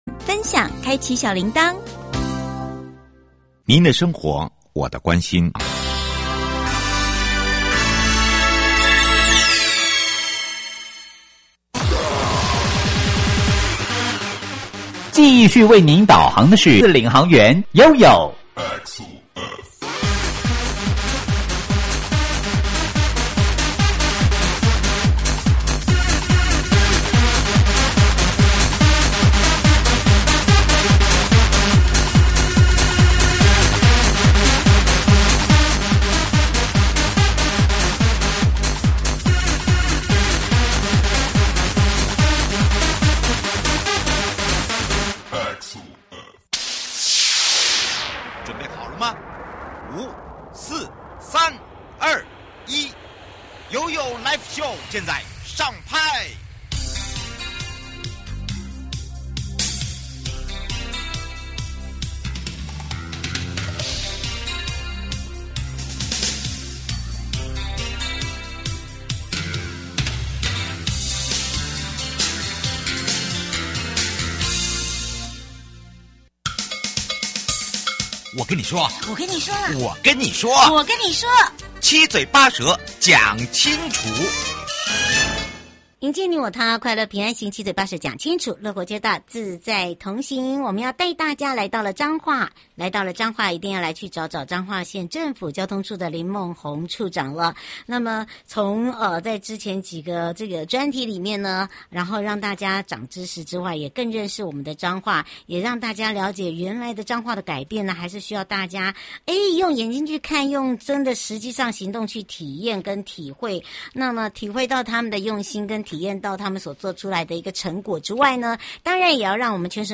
受訪者： 1.國土署都市基礎工程組 2.彰化縣政府交通處林孟弘處長